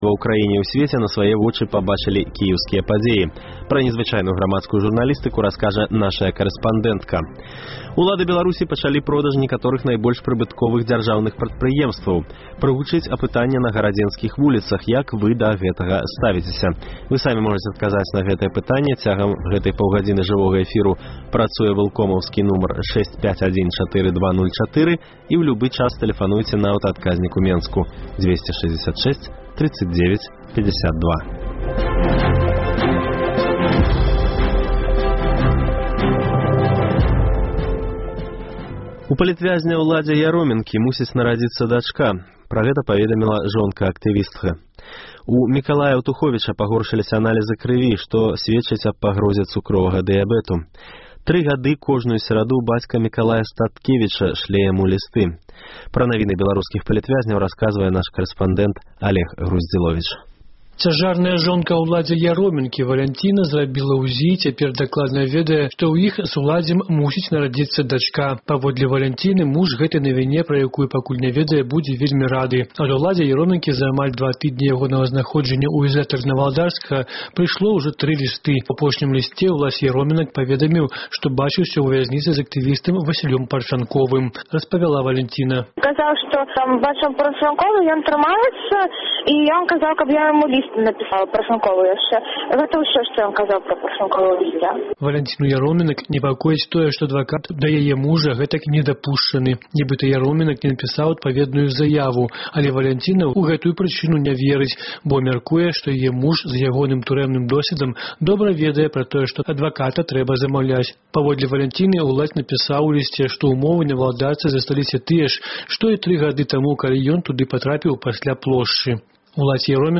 Паведамленьні нашых карэспандэнтаў, госьці ў жывым эфіры, званкі слухачоў, апытаньні ў гарадах і мястэчках Беларусі.